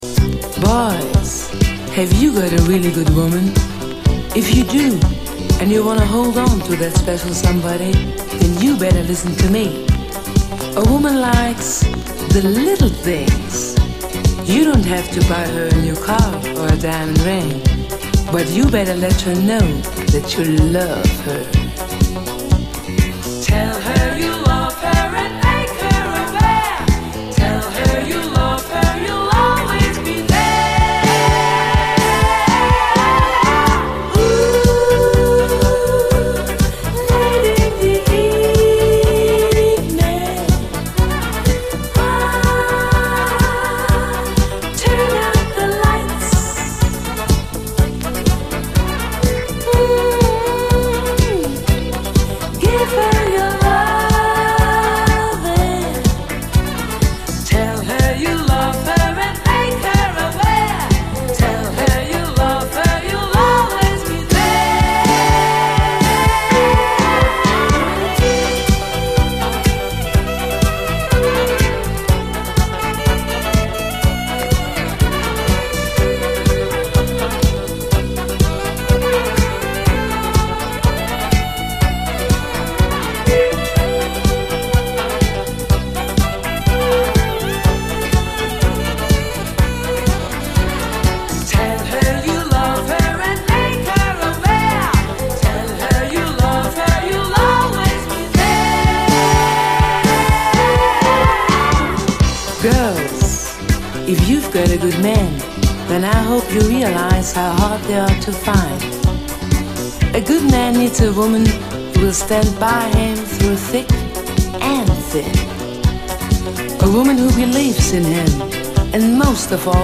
SOUL, 70's～ SOUL, DISCO, 7INCH
フリーソウル・ライクなユーロ産流麗ソウルフル・ダンサー！